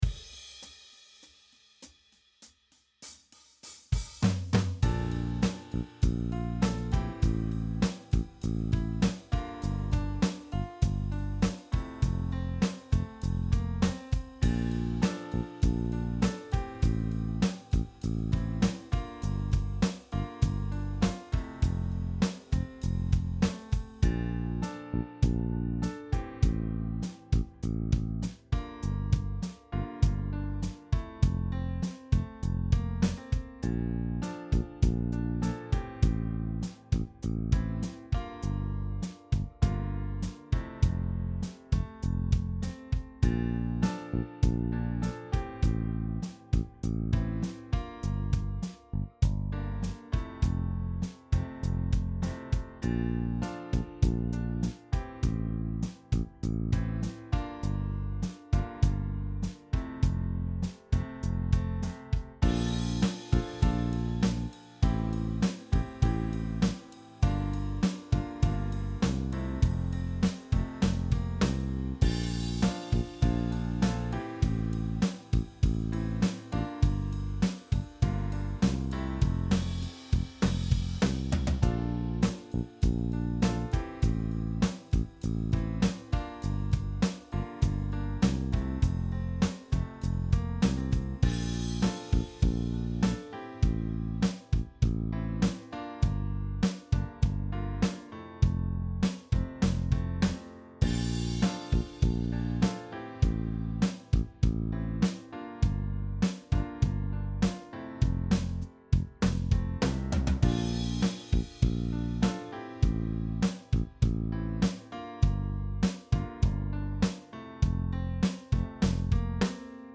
Cover version
Guitar chords, lyrics free backing track. Soul, jazzy style.